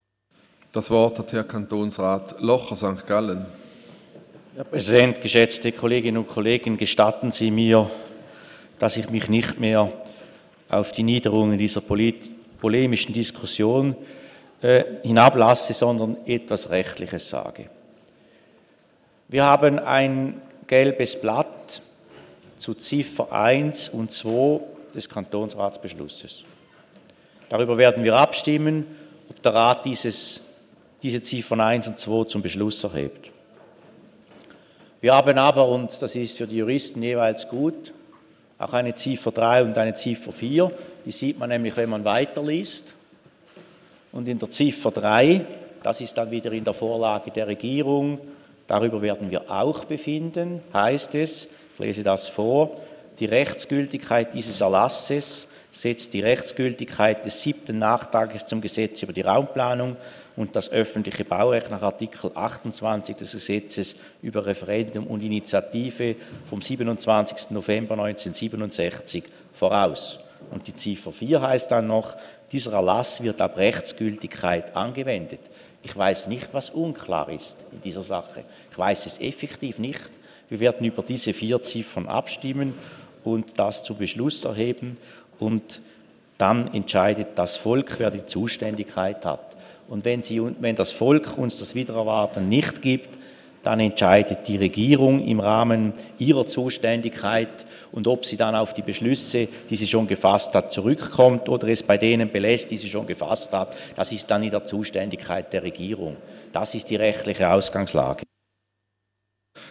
16.9.2015Wortmeldung
Session des Kantonsrates vom 14. bis 16. September 2015